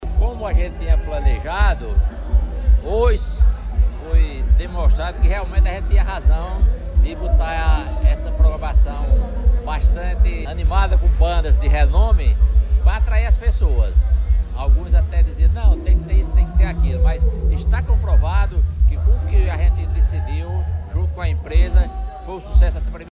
Ouça a avaliação do secretário de Cultura, Pedro Leitão: